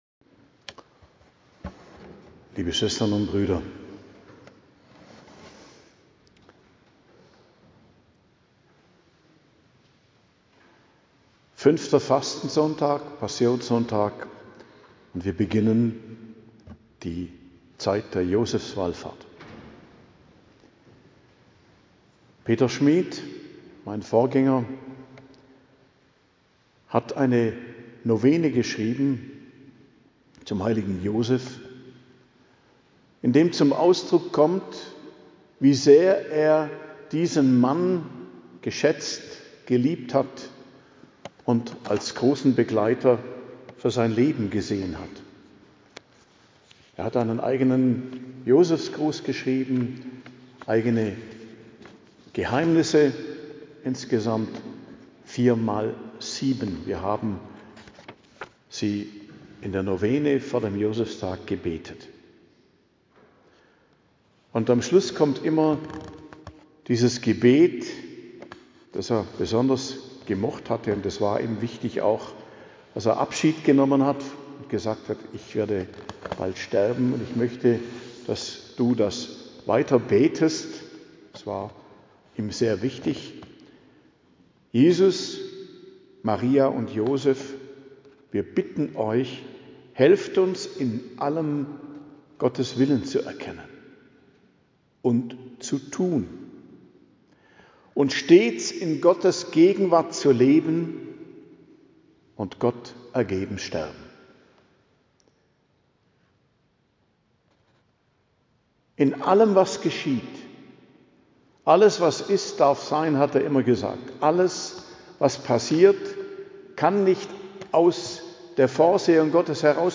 Predigt zum 5. Fastensonntag, 22.03.2026 ~ Geistliches Zentrum Kloster Heiligkreuztal Podcast